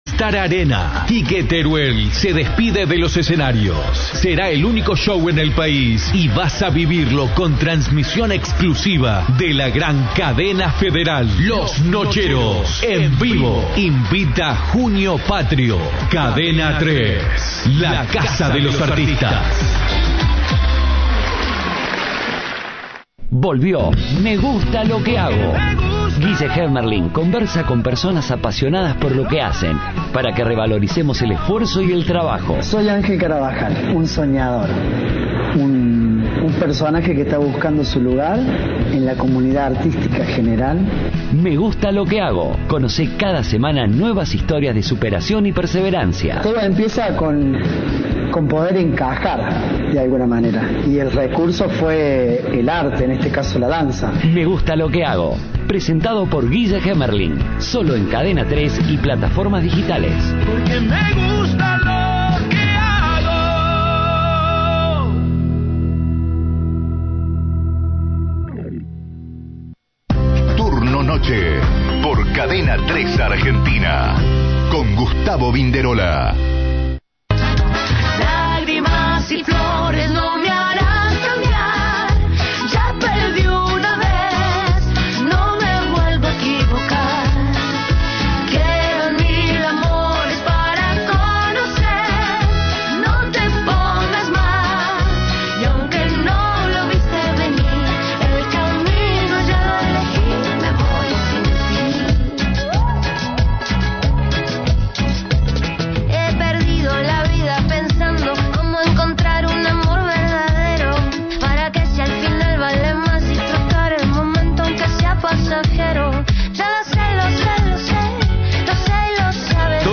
El cantante jujeño estuvo en los estudios de Cadena 3 y brindó un adelanto de su presentación de este viernes en la sala principal del teatro de Ciudad de Las Artes.